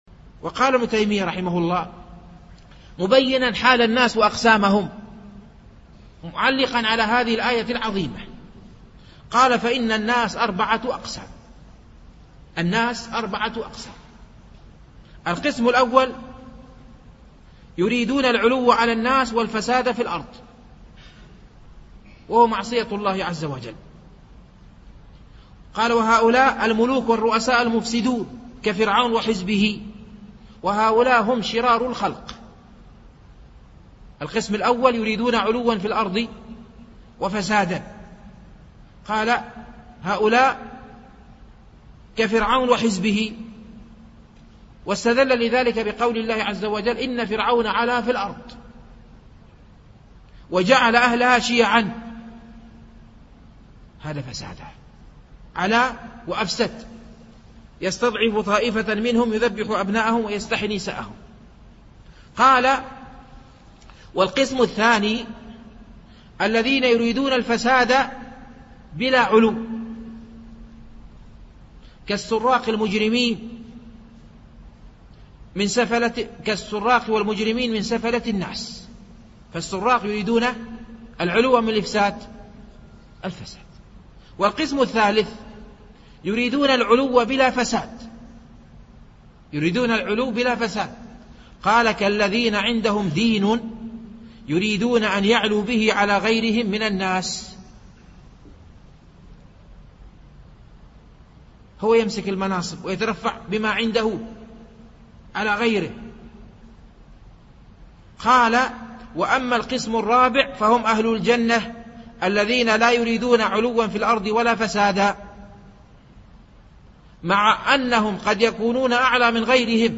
MP3 Mono 22kHz 32Kbps (VBR)